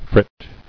[frit]